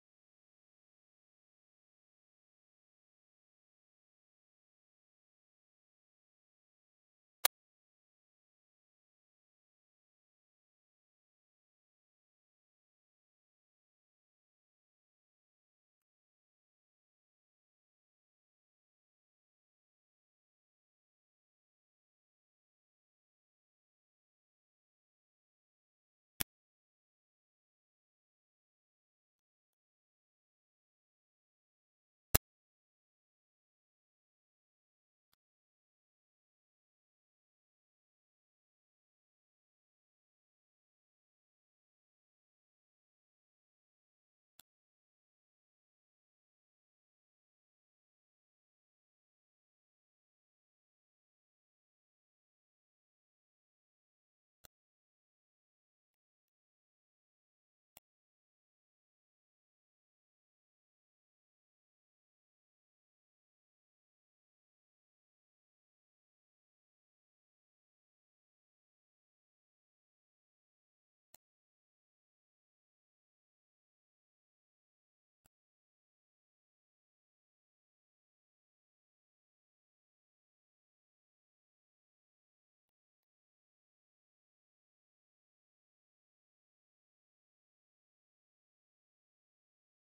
country - guitare - bluesy - cosy - harmonica